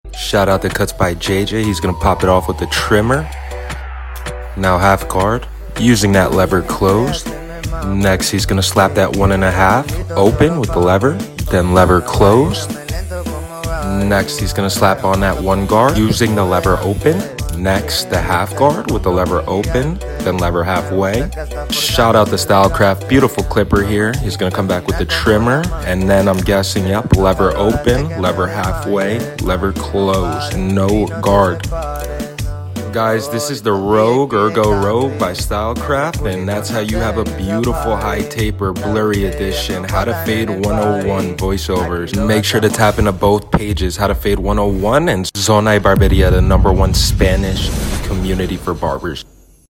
POV Barber with Step By Step 🪜 + Voiceover